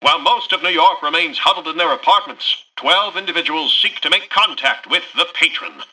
Newscaster_headline_04.mp3